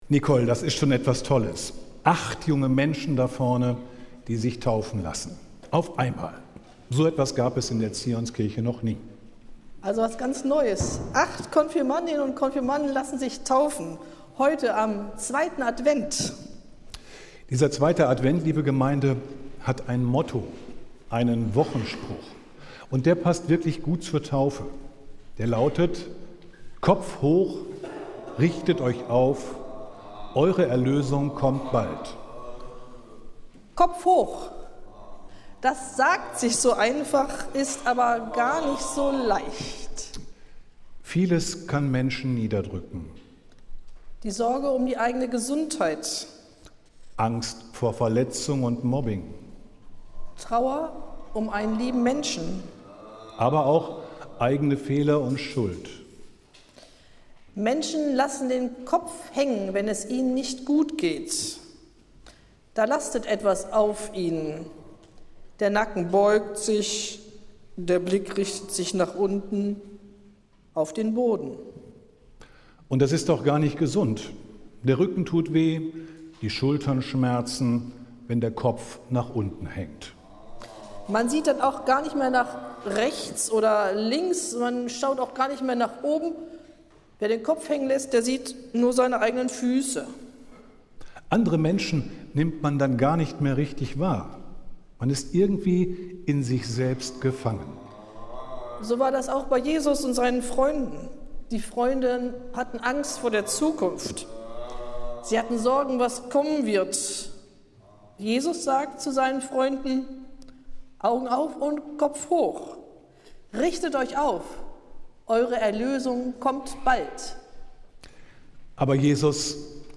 Predigt des Gottesdienstes in einfacher Sprache aus der Zionskirche am Sonntag, den 7. Dezember 2025